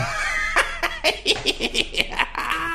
Joker Laugh 2
crazy evil hamill insane joker laugh laughing laughter sound effect free sound royalty free Funny